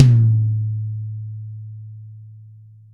Index of /90_sSampleCDs/Best Service - Real Mega Drums VOL-1/Partition H/DRY KIT 2 GM